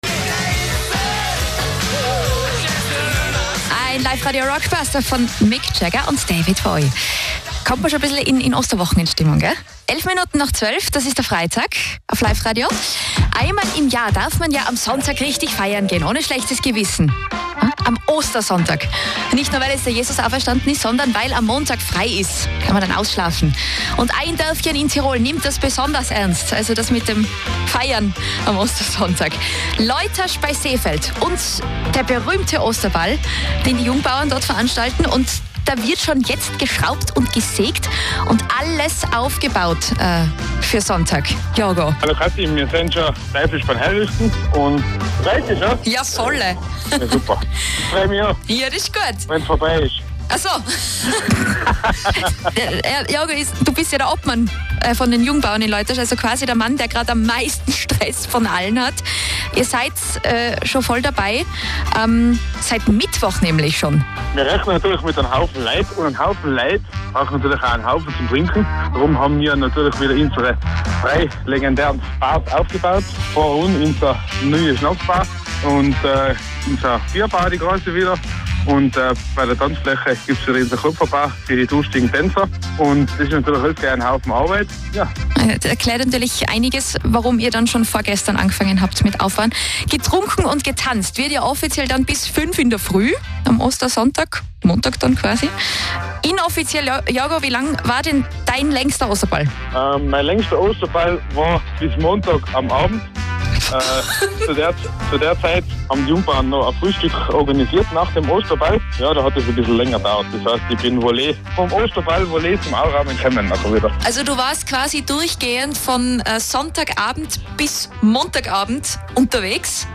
Interview_Liferadio.mp3